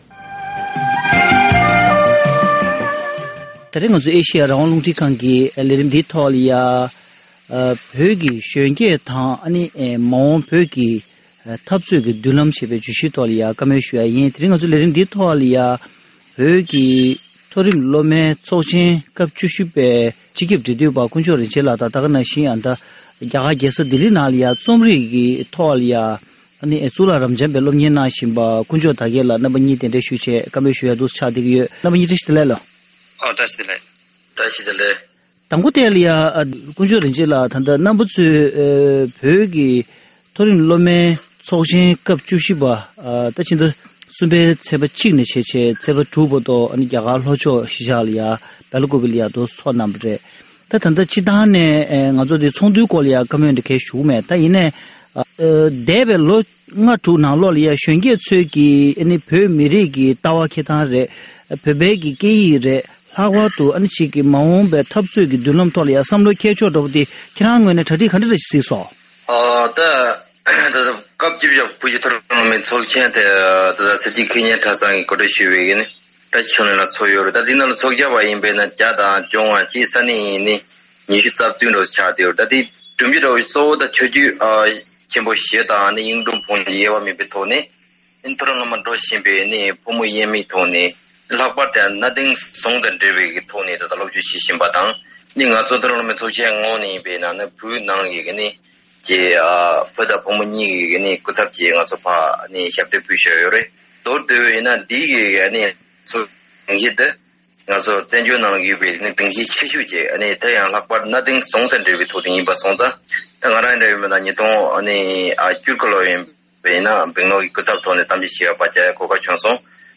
བོད་ཀྱི་གཞོན་སྐྱེས་དང་མ་འོངས་བོད་ཀྱི་མདུན་ལམ་ཞེས་པའི་བརྗོད་གཞིའི་ཐོག་གླེང་མོལ་ཞུས་པ།